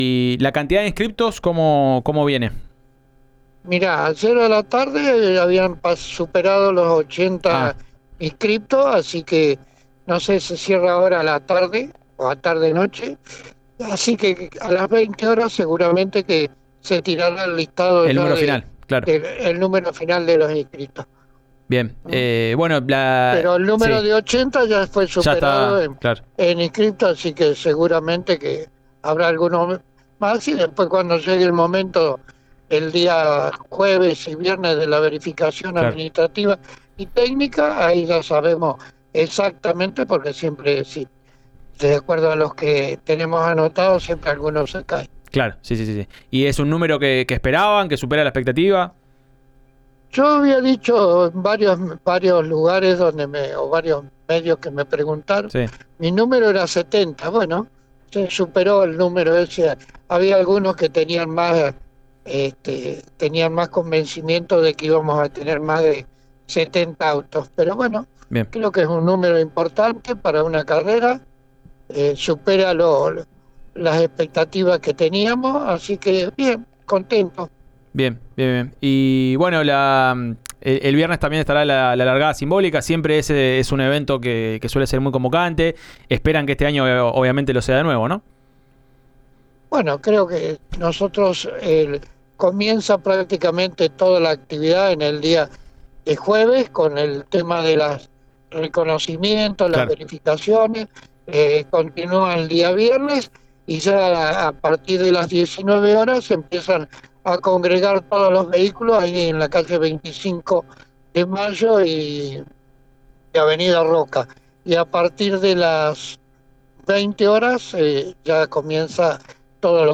en diálogo con “Subite al Podio” de Río Negro Radio.